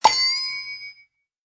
mailbox_alert.ogg